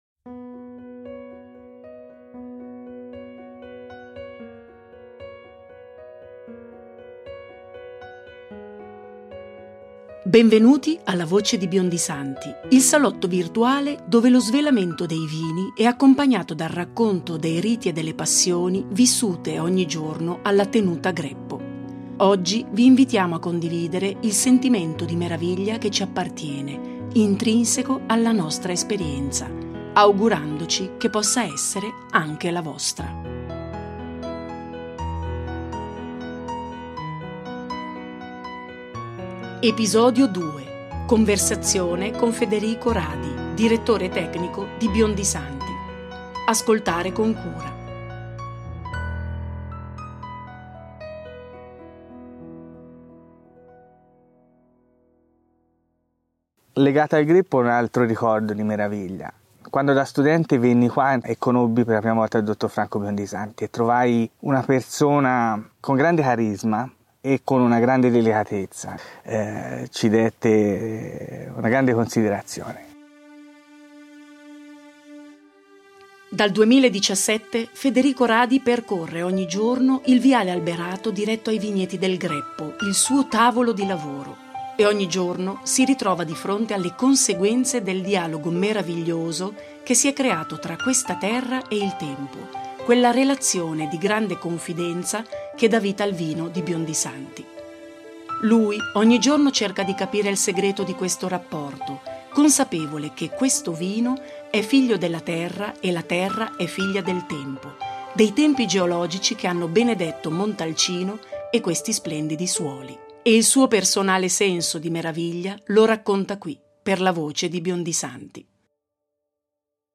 Conversazione